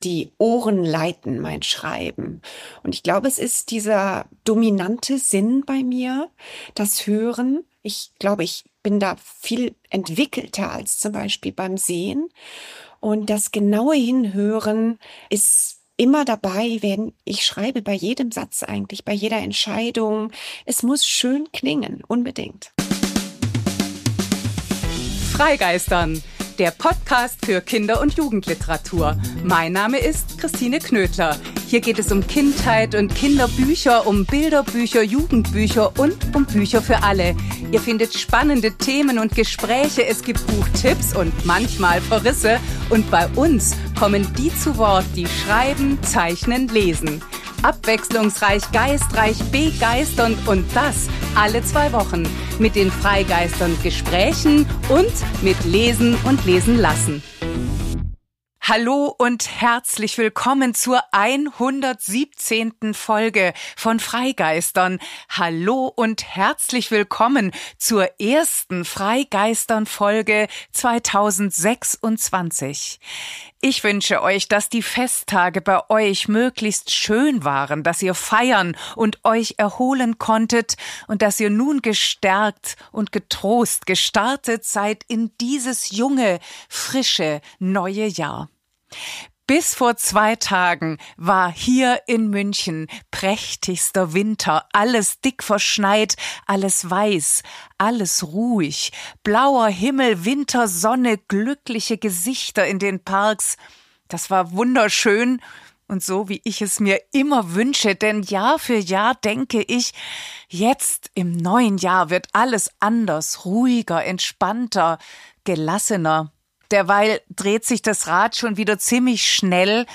Beschreibung vor 3 Monaten Die 117. freigeistern!-Folge, das erste freigeistern!-Gespräch in diesem noch so jungen neuen Jahr soll mit Perspektivwechseln beginnen, denn das brauchen wir in maximal unsicheren, oft aussichtslosen Zeiten.